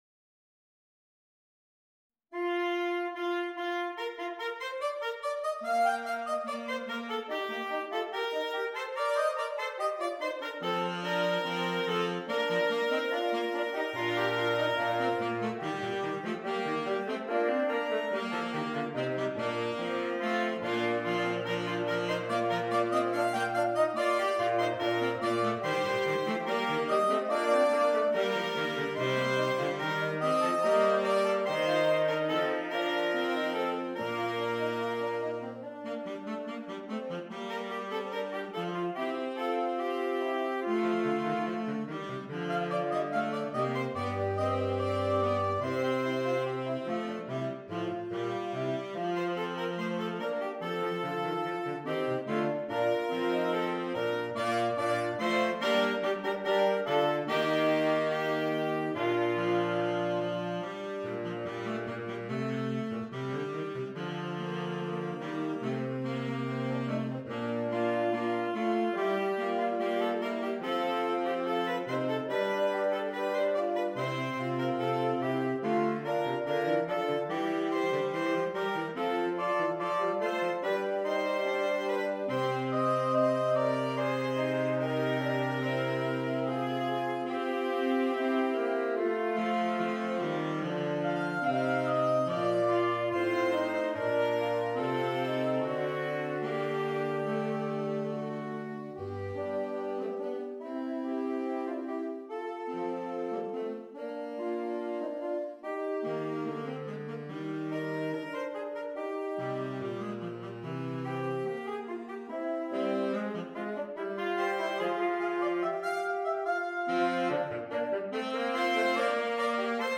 Saxophone Quartet (SATB or AATB)